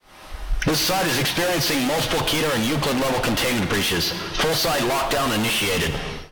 KLlvIohPNCi_Alarm2.ogg